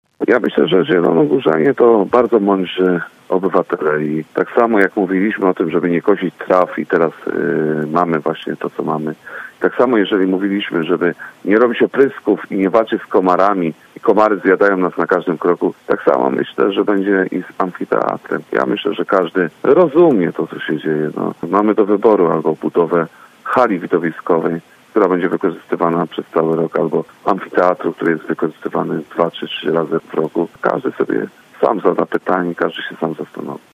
Myślę, że każdy rozumie to, co się dzieje – mówił przedstawiciel Polskiego Stronnictwa Ludowego: